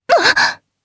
Lewsia_B-Vox_Damage_jp_03.wav